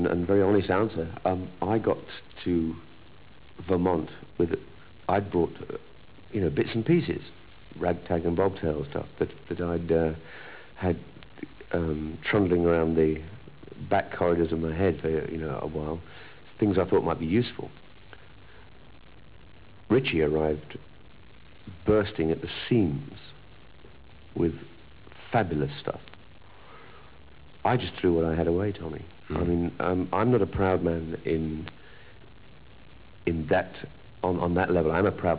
THE TOMMY VANCE INTERVIEWS